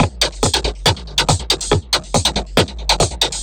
tx_perc_140_picked2.wav